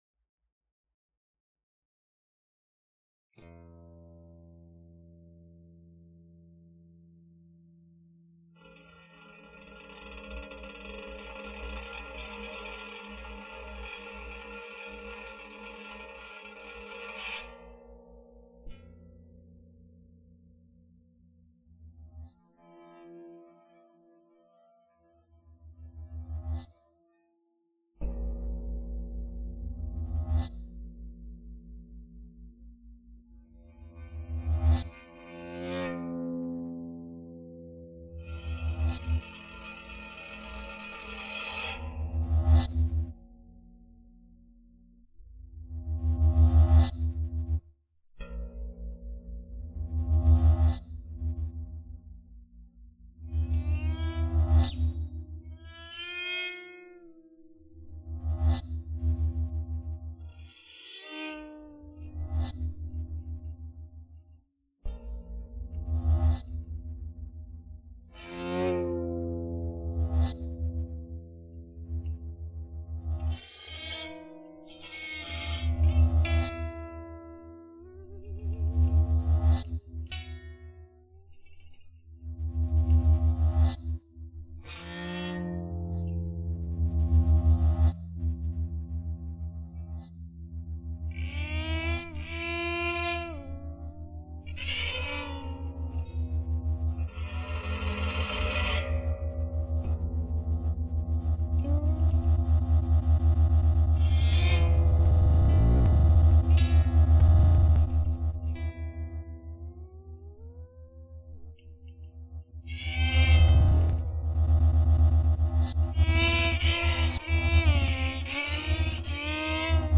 Improvisation (2014) for zithryan and electronics Listen: (Stereo | 6-channel) Sorry.
electronics, live sampling
zythrian Program Recorded by the Dept. of Performance Studies at Texas A&M University.